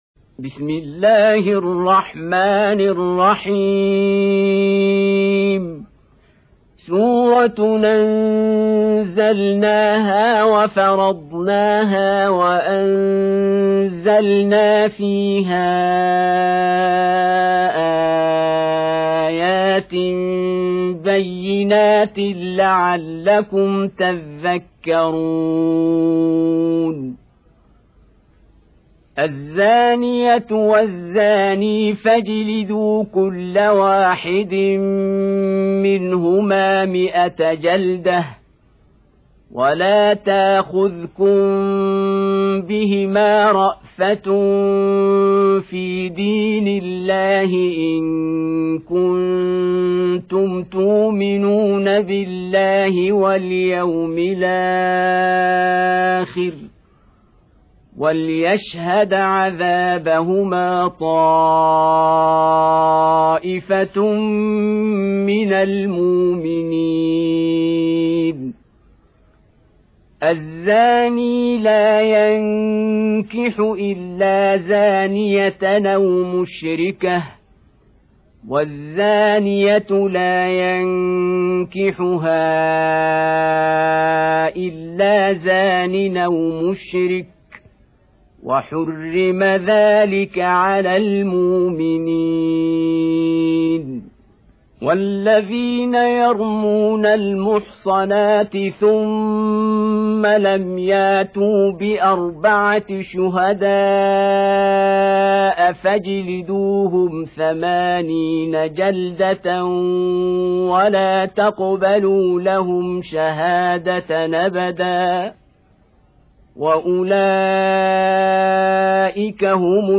24. Surah An-N�r سورة النّور Audio Quran Tarteel Recitation
Surah Sequence تتابع السورة Download Surah حمّل السورة Reciting Murattalah Audio for 24.